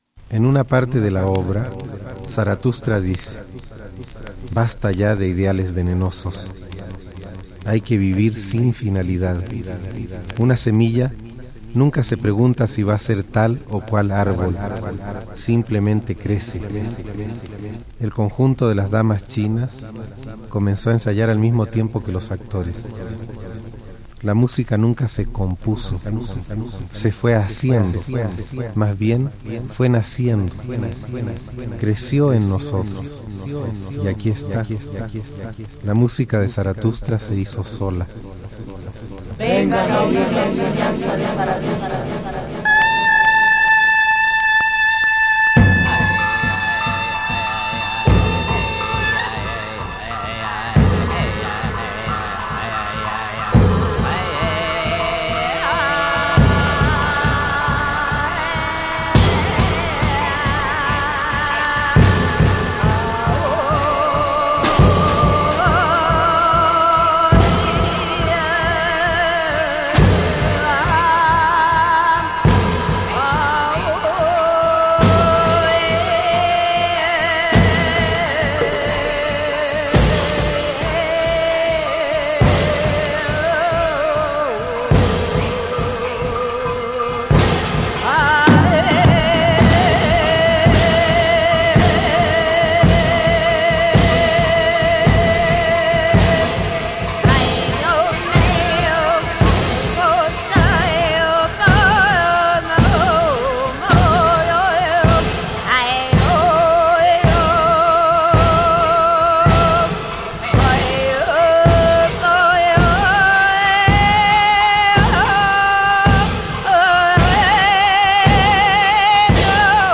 Funk/Soul International Psych